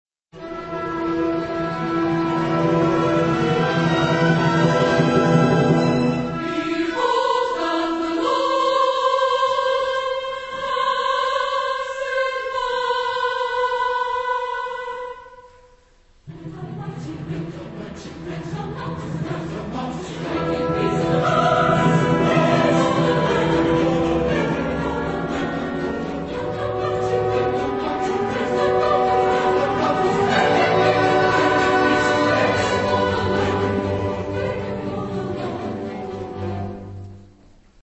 Genre-Style-Form: Sacred ; Oratorio chorus
Type of Choir: SATB  (4 mixed voices )
Instrumentation: Orchestra
Tonality: E major